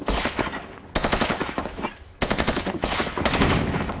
gun.wav